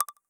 ClickyButton9a.wav